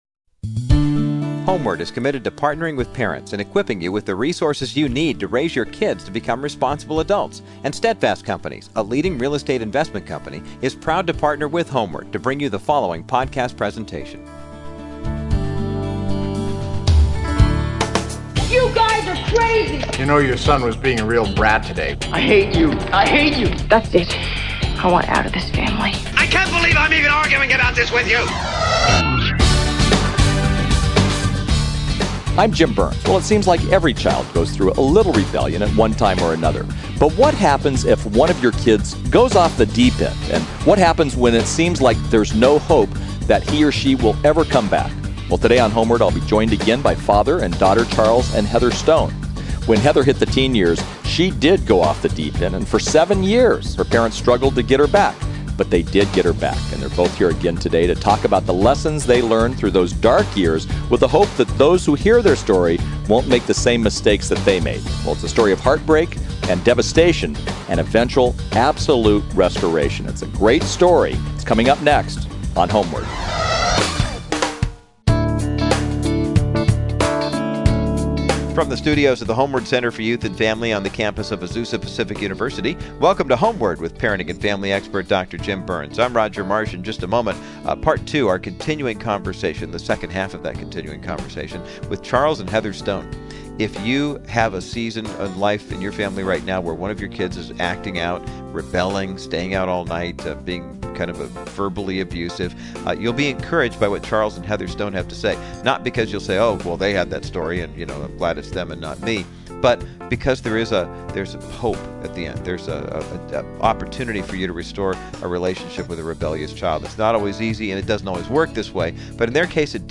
If you’re the parent of a rebellious child, check out this powerful conversation